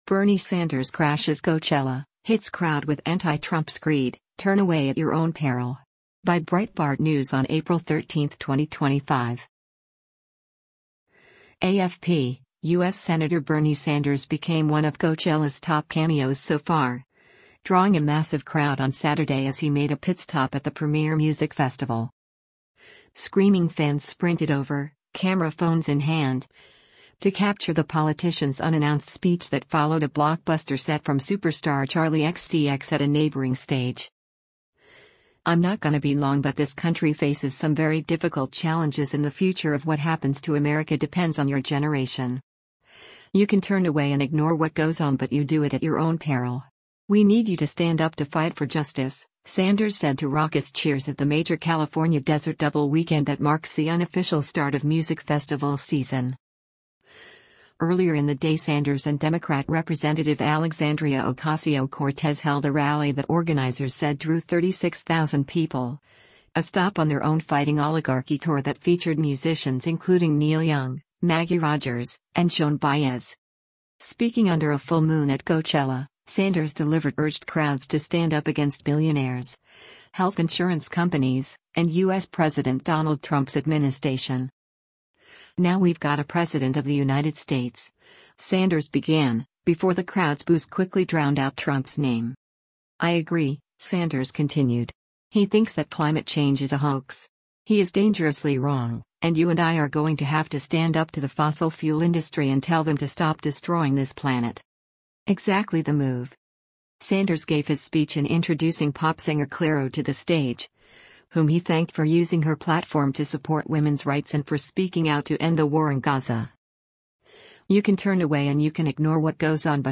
Bernie Sanders speaks onstage before Clario's performance at the 2025 Coachella Valley Mus
“I’m not gonna be long but this country faces some very difficult challenges and the future of what happens to America depends on your generation. You can turn away and ignore what goes on but you do it at your own peril. We need you to stand up to fight for justice,” Sanders said to raucous cheers at the major California desert double weekend that marks the unofficial start of music festival season.
“Now we’ve got a president of the United States,” Sanders began, before the crowd’s boos quickly drowned out Trump’s name.